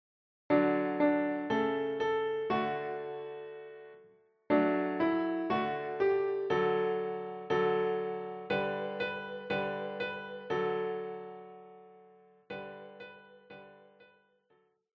Instrumente